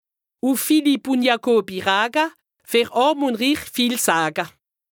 Haut Rhin
Ville Prononciation 68
Bruebach